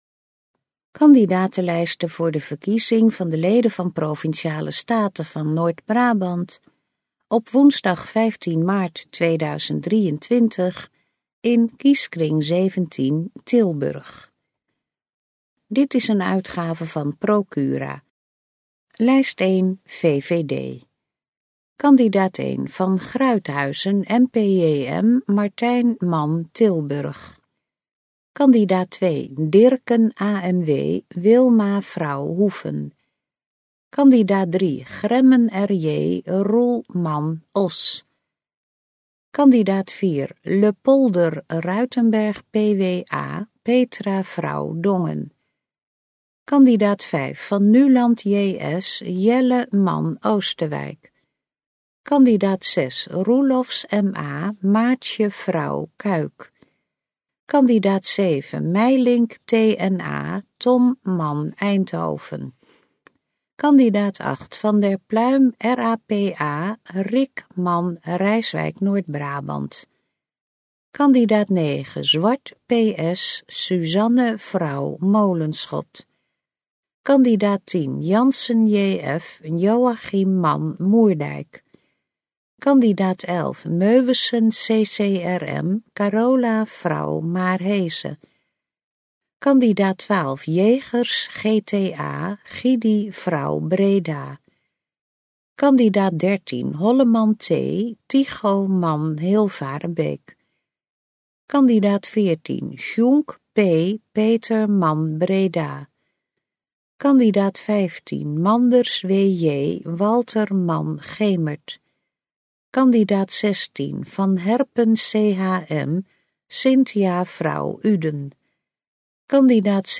Gesproken kandidatenlijsten | Gemeente Geertruidenberg